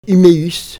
uitspraak Unéus.